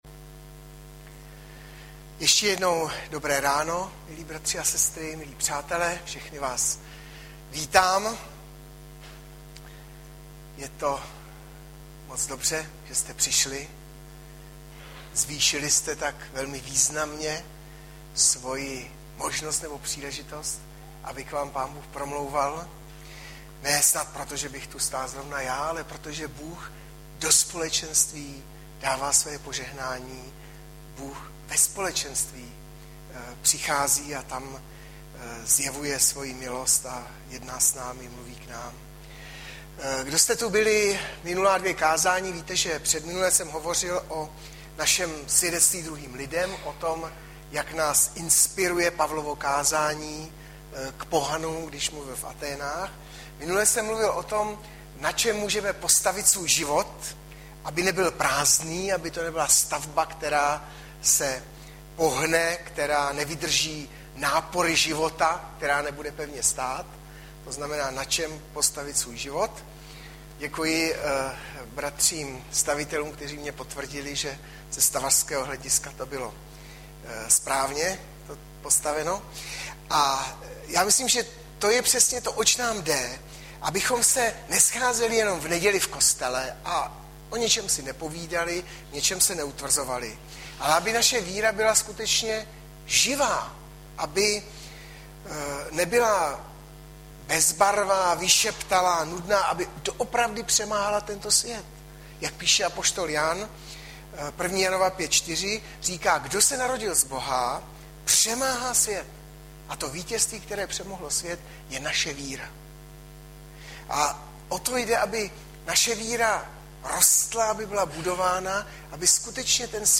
24.06.2012 - BOŽÍ MOC VIDITELNÁ aneb kázání na dovolenou - Sk 3,1-11
Audiozáznam kázání si můžete také uložit do PC na tomto odkazu.